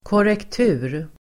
Uttal: [kårekt'u:r]